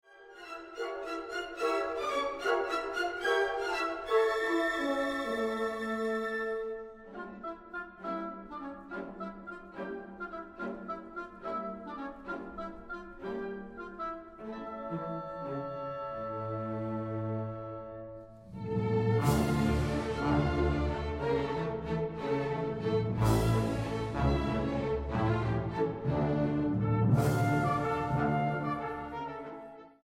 9 Vals.